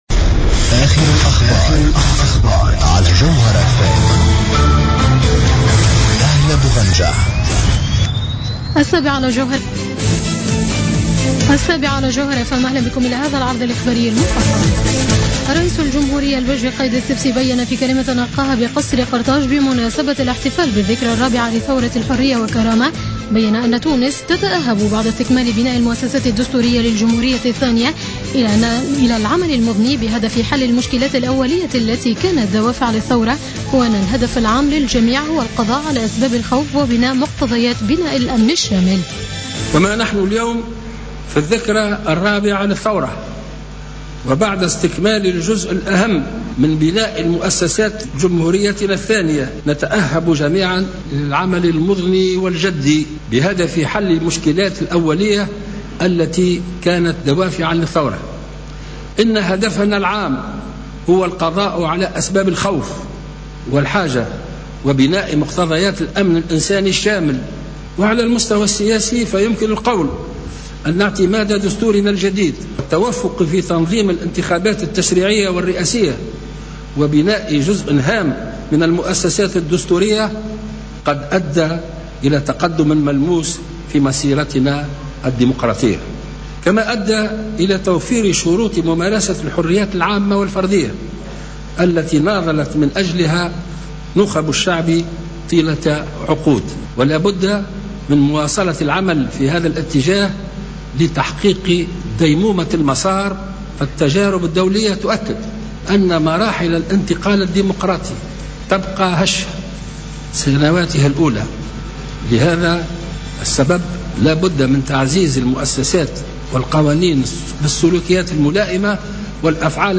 نشرة أخبار السابعة مساء ليوم الاربعاءر14-01-15